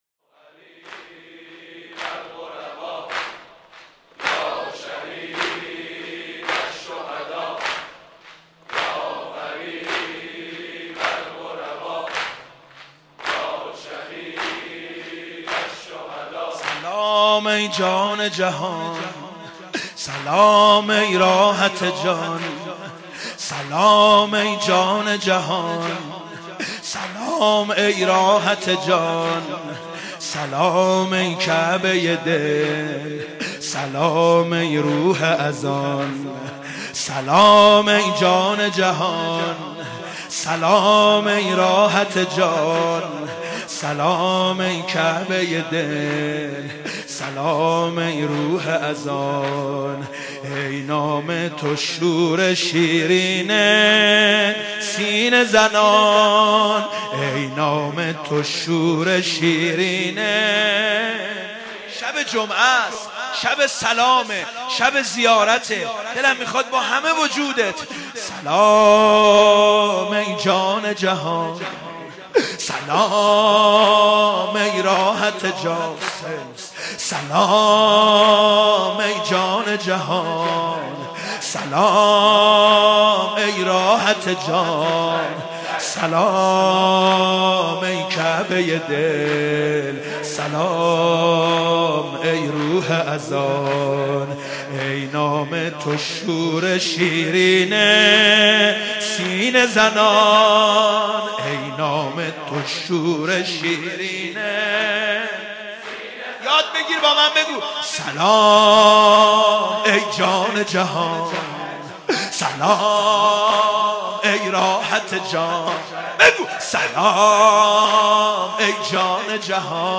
مداحی جدید
شب چهارم محرم97 تهران مسجد امیر